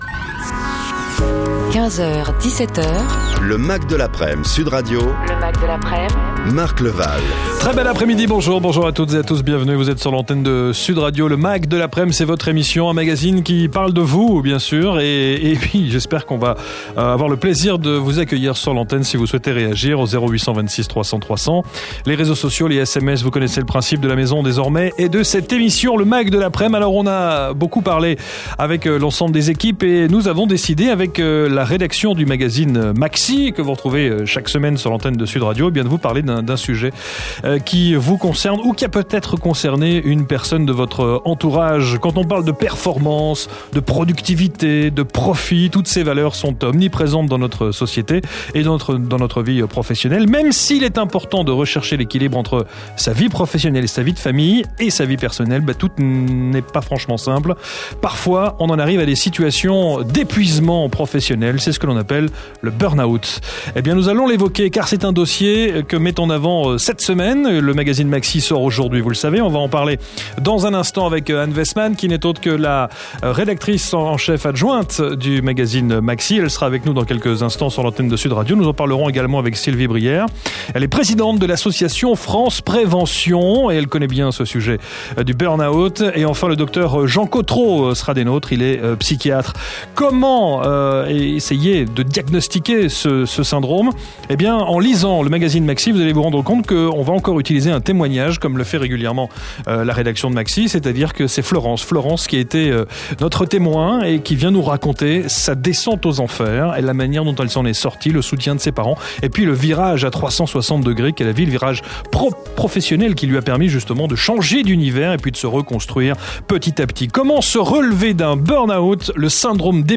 Mes PASSAGES EN RADIO sur le sujet du travail :